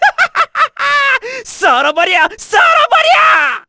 Bernheim-Vox_Skill6_kr_z.wav